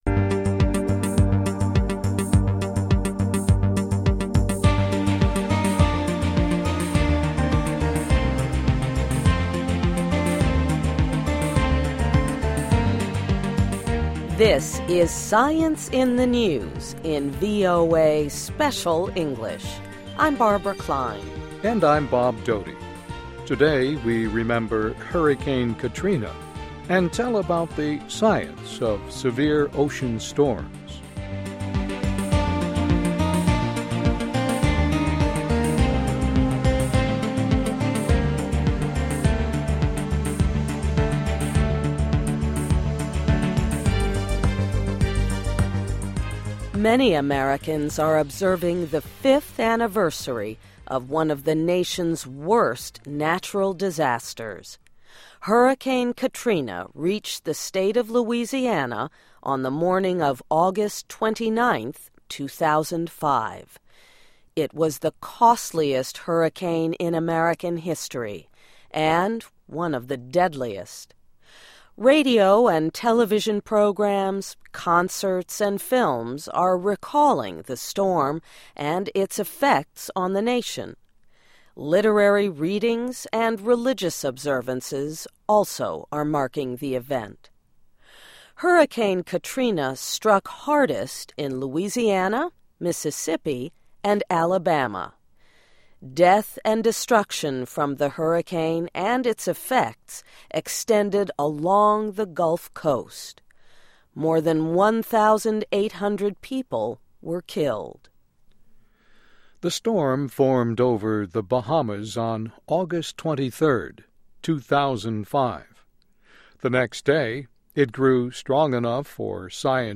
This is SCIENCE IN THE NEWS, in VOA Special English.